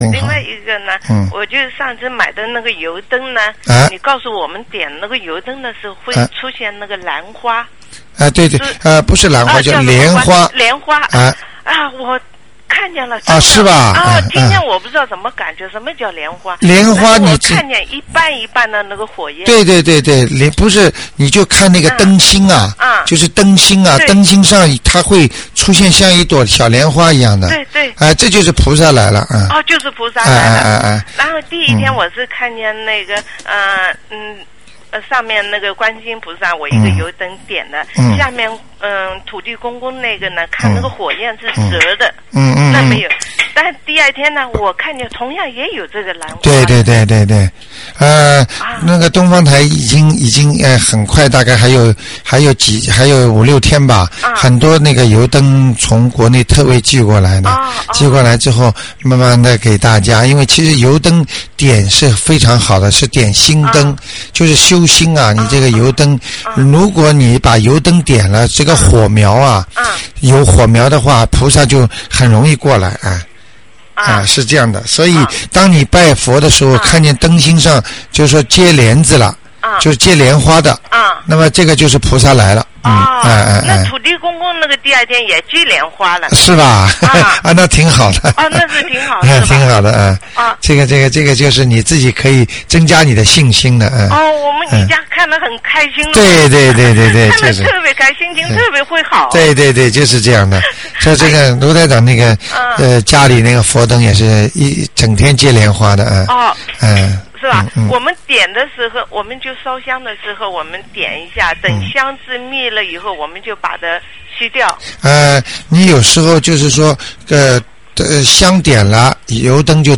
目录：☞ 2008年05月_剪辑电台节目录音集锦